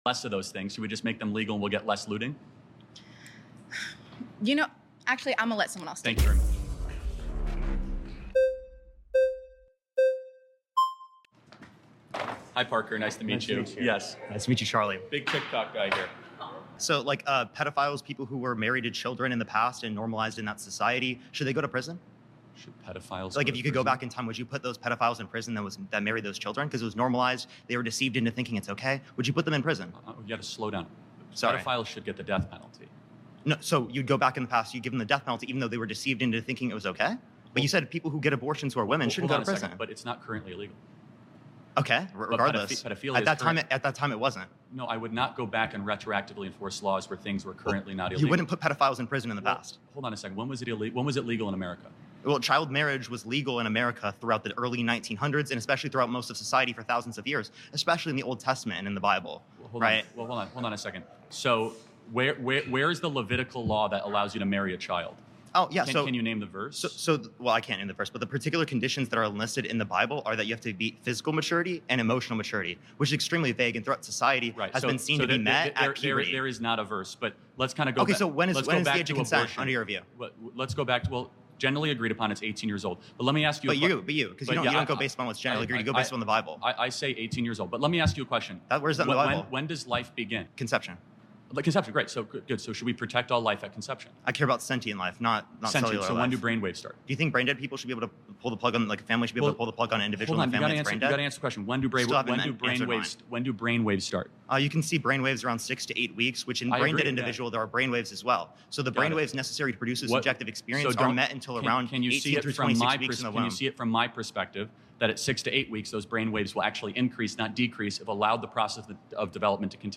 Charlie kirk debate part 6 sound effects free download